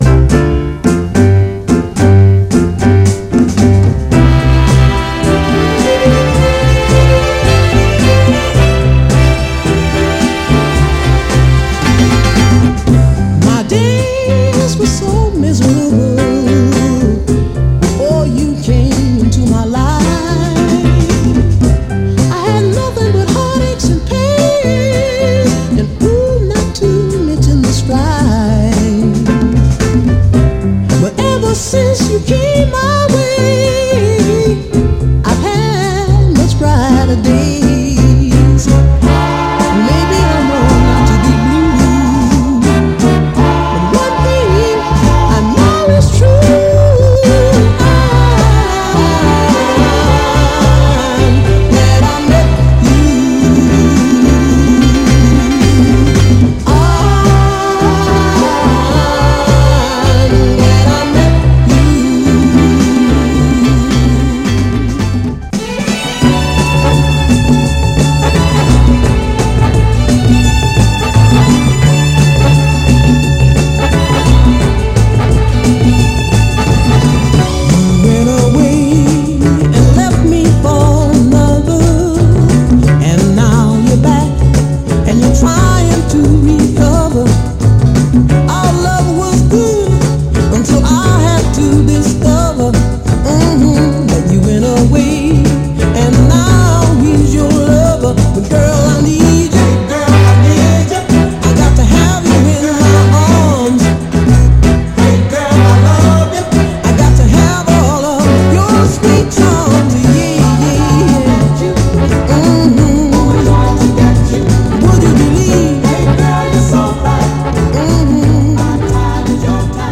ぶっとい音色で聴かせるクロスオーヴァー・ソウル
※試聴音源は実際にお送りする商品から録音したものです※